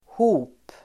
Uttal: [ho:p]